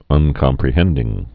(ŭnkŏm-prĭ-hĕndĭng)